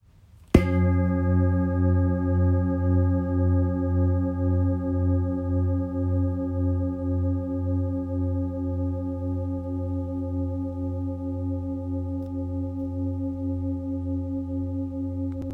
Large Etched Bowl with Seven Chakra Symbols Tibetan – 39cm
Rich and resonant, perfect for meditation, sound baths, and energy work.
The bowl measures 39cm in diameter.
Tibetan-chakra-nada-1.m4a